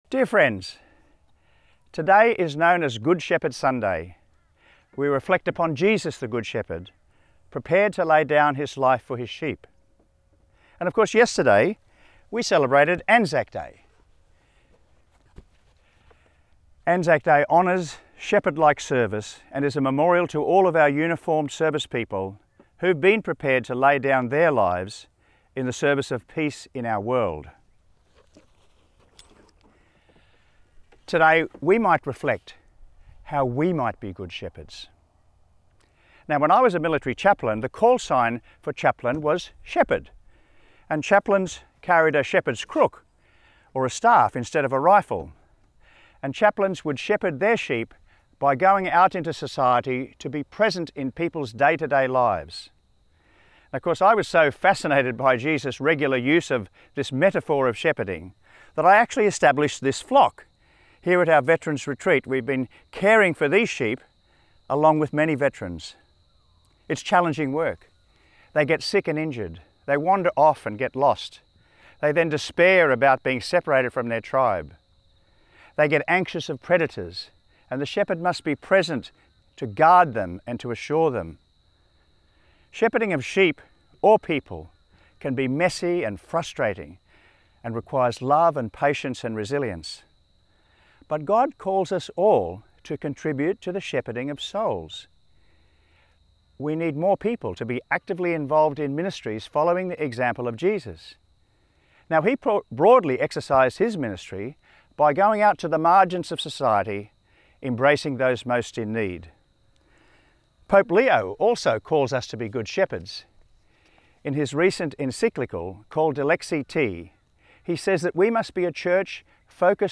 Archdiocese of Brisbane Fourth Sunday of Easter - Two-Minute Homily